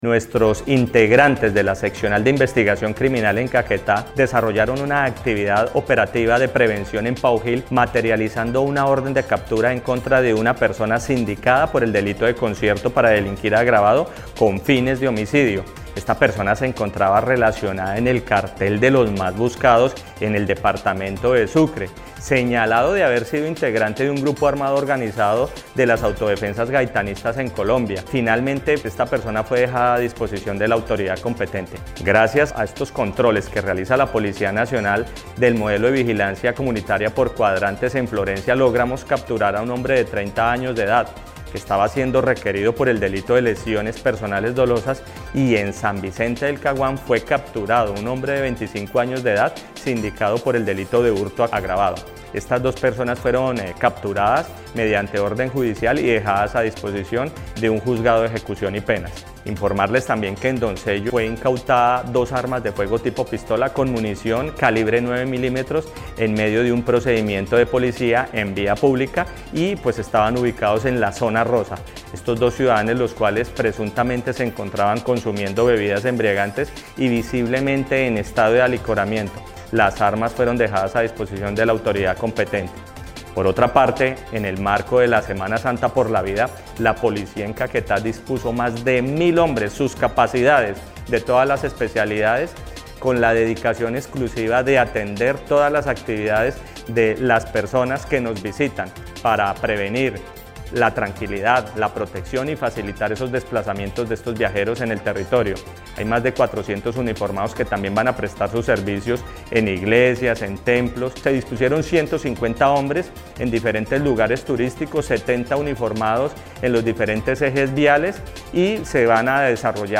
El coronel Javier Castro Ortega, comandante de la policía Caquetá, explicó que el capturado deberá responder por el delito de concierto para delinquir agravado con fines de homicidio, y era señalado de haber sido integrante de un grupo armado organizado.